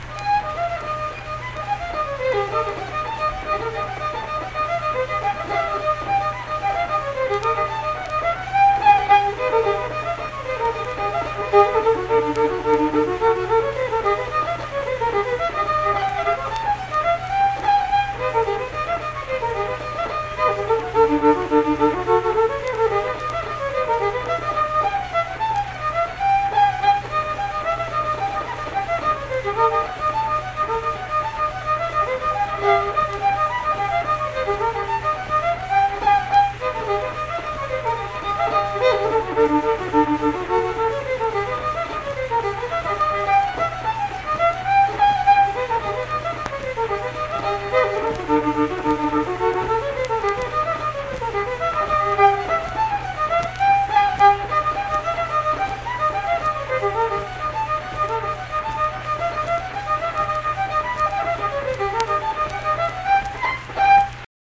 Unaccompanied fiddle music
Instrumental Music
Fiddle
Pleasants County (W. Va.), Saint Marys (W. Va.)